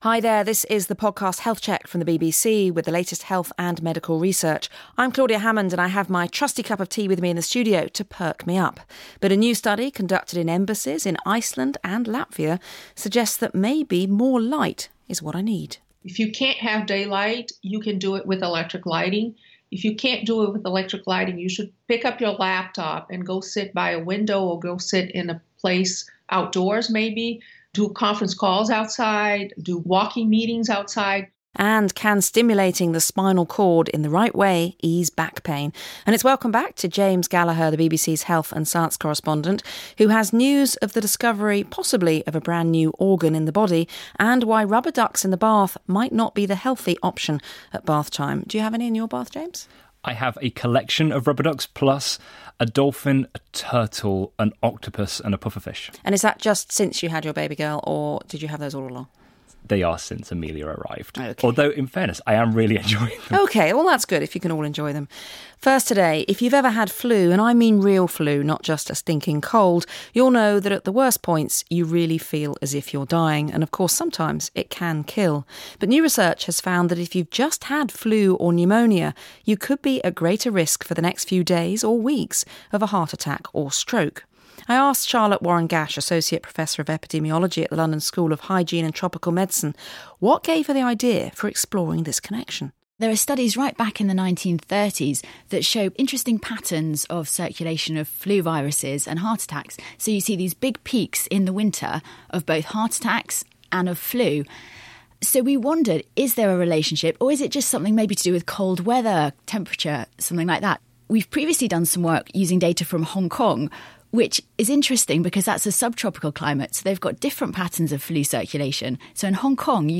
"Health Check" BBC World News - March 28, 2018 Listen to this BBC report on LRC Office Lighting research [story starts at 18:00 minutes]